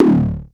KLONE_DTOM037.wav